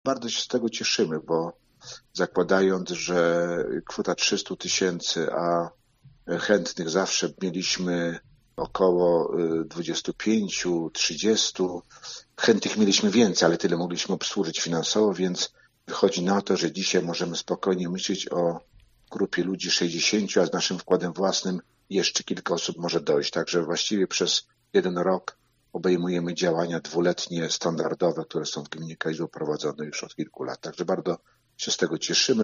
O dofinansowaniu mówi wójt gminy, Dariusz Łukaszewski: